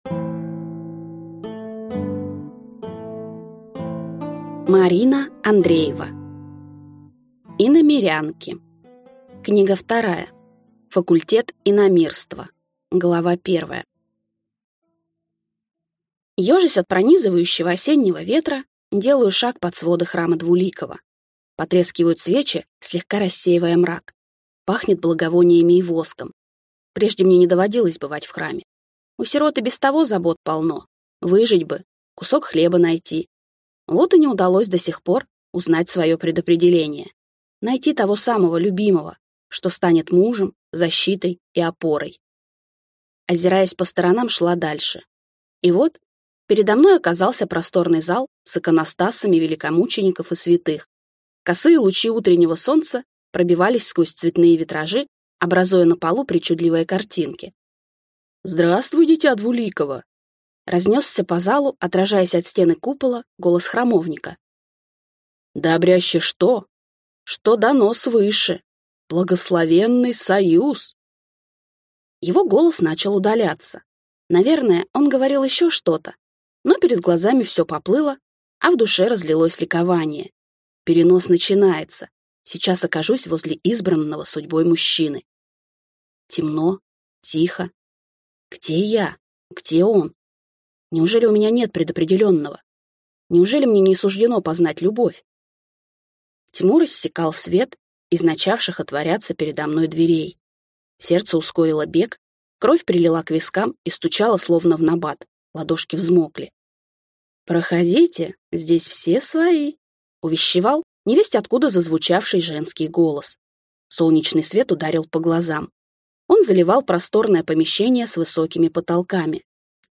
Аудиокнига Факультет иномирства | Библиотека аудиокниг
Прослушать и бесплатно скачать фрагмент аудиокниги